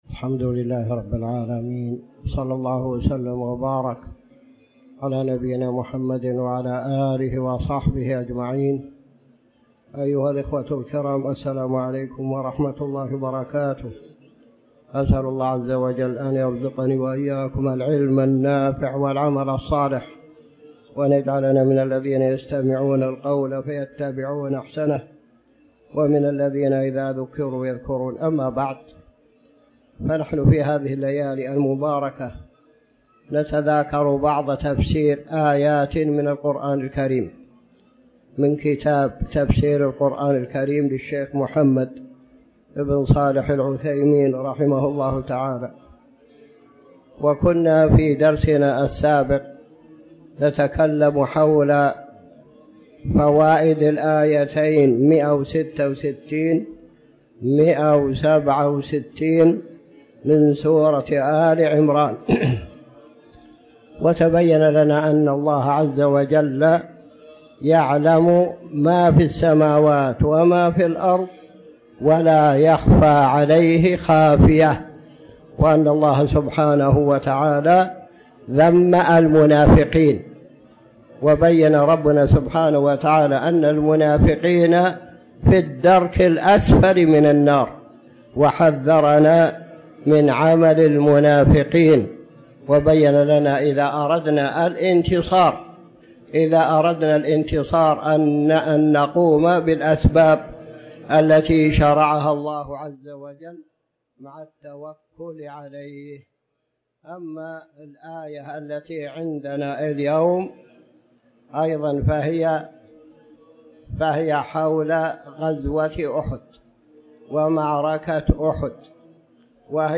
تاريخ النشر ١٩ ربيع الأول ١٤٤٠ هـ المكان: المسجد الحرام الشيخ